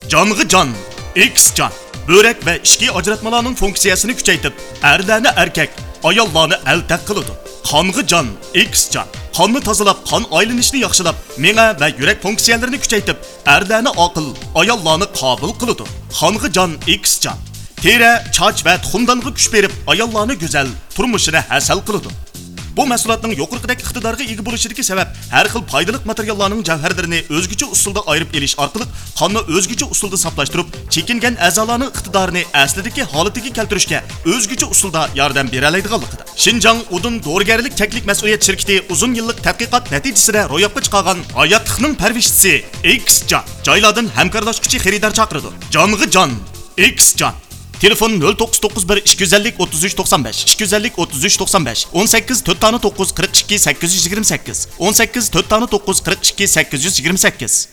维吾尔语样音试听下载
uygur-male1-sample.mp3